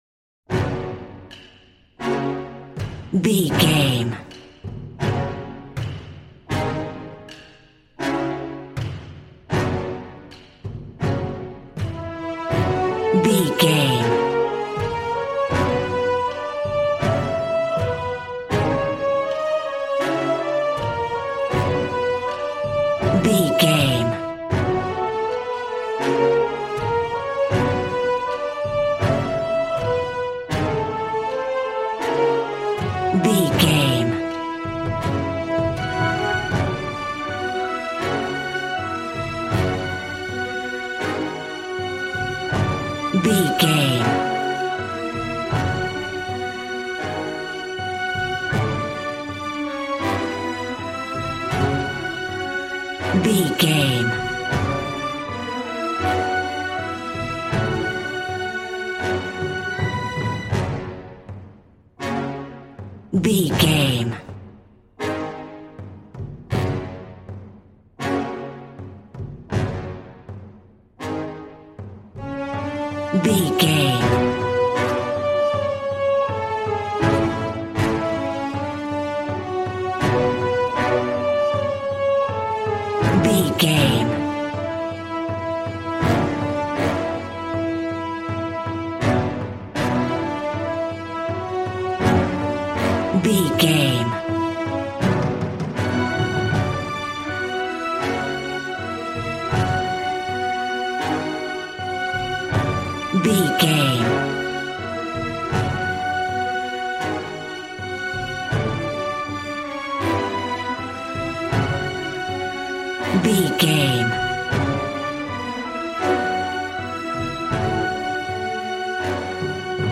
Valiant and Triumphant music for Knights and Vikings.
Regal and romantic, a classy piece of classical music.
Aeolian/Minor
G♭
brass
strings
violin